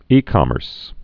(ēkŏmərs)